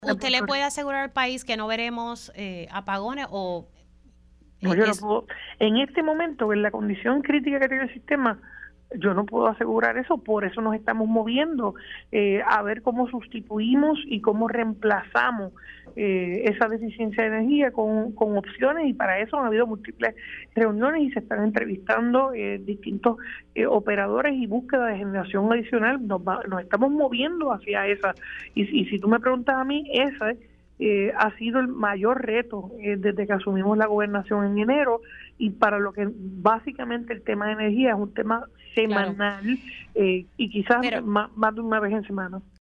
La gobernadora Jenniffer González reconoció en Pega’os en la Mañana que no puede garantizar que no habrán relevos de carga en la energía eléctrica este verano.
121-JENNIFFER-GONZALEZ-GOBERNADORA-NO-PUEDE-GARANTIZAR-QUE-NO-HABRA-RELEVOS-DE-CARGA.mp3